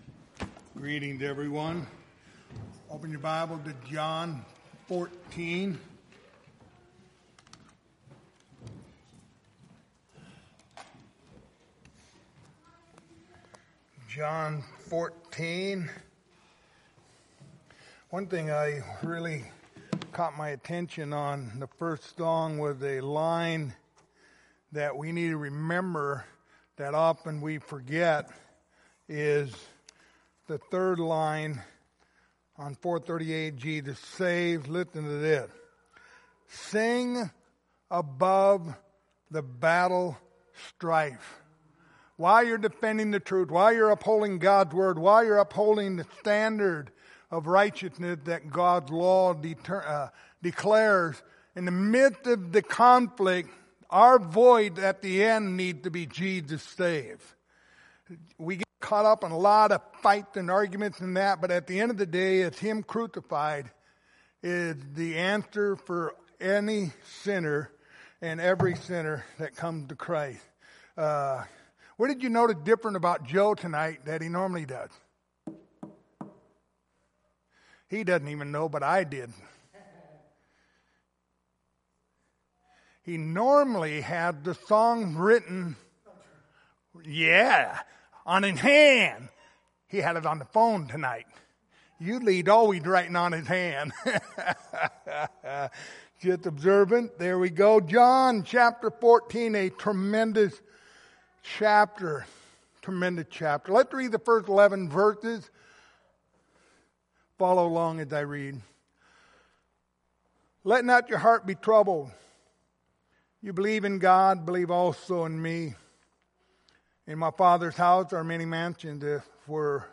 Passage: John 14:1-11 Service Type: Wednesday Evening